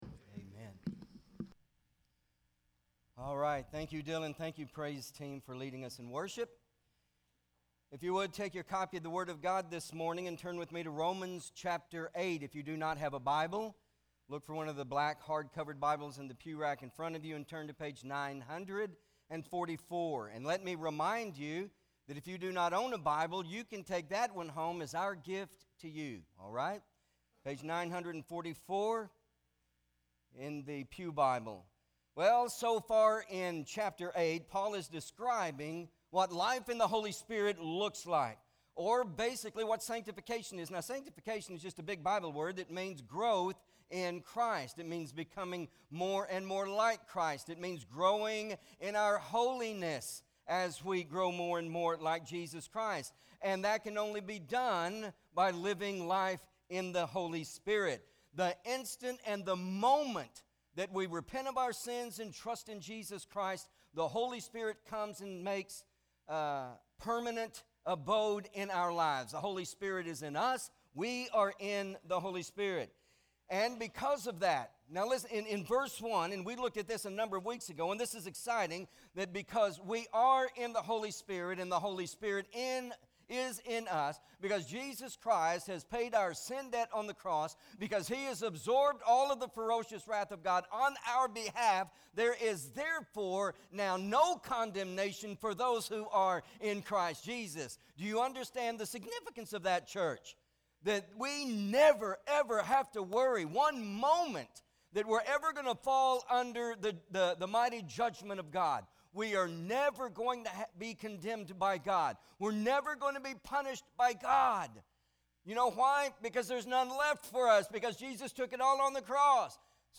Romans Revealed- The Holy Spirit and Heirship MP3 SUBSCRIBE on iTunes(Podcast) Notes Sermons in this Series Romans 8: 16-18 Not Ashamed!